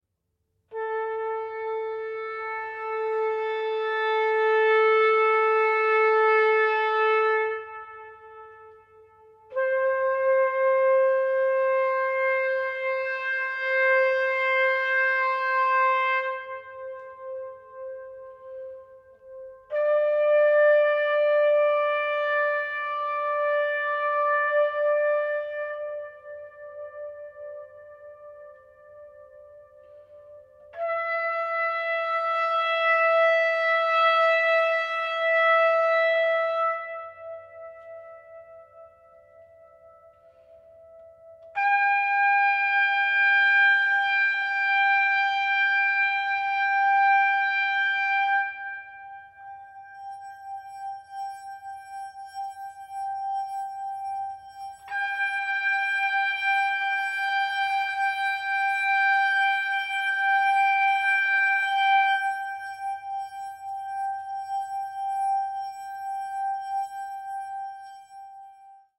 electro-acoustic solo works